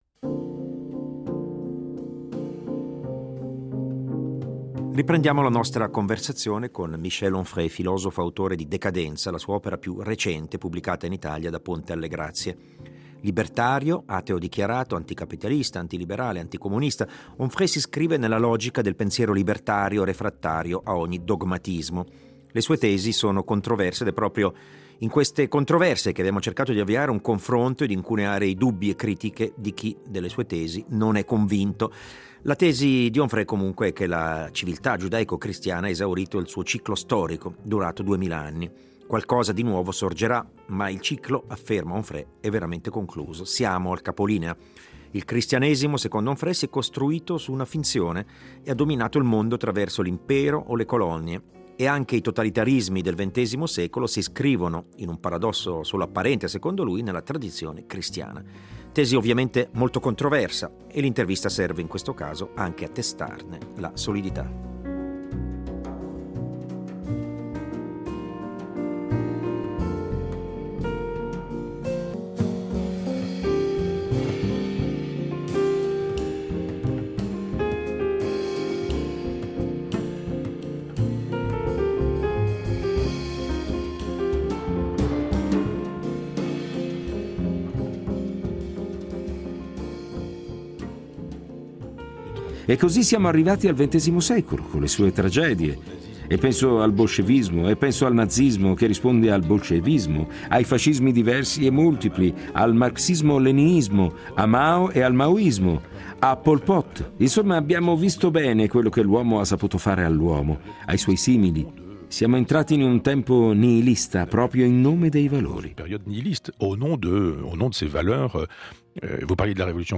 L’intervista tra spunto dalla pubblicazione del secondo volume di una triade molto ambiziosa che mira a interpretare il mondo contemporaneo nel suo insieme e in modo interdisciplinare. Dopo "Cosmo" l’editore Ponte alla Grazie ha da poco pubblicato "Decadenza" , imponente trattato che ipotizza la fine di un’era, quella della civiltà giudaico-cristiana.